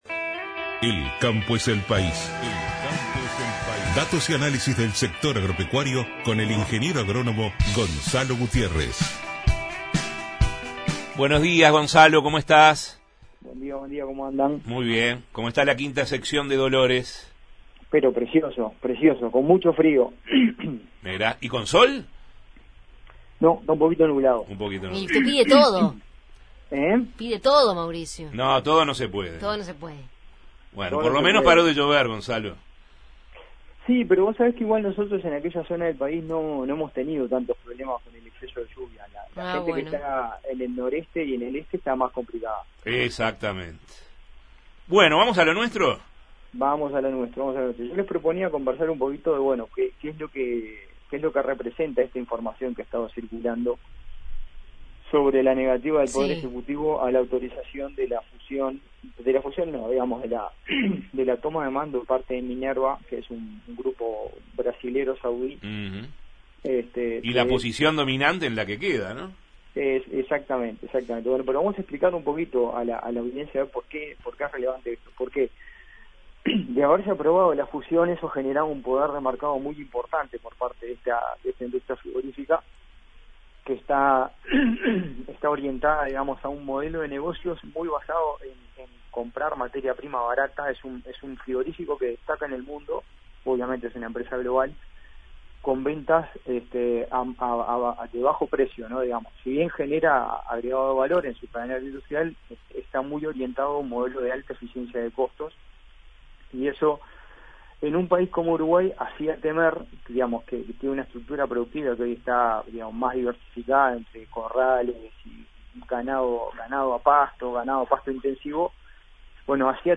El campo es el país, columna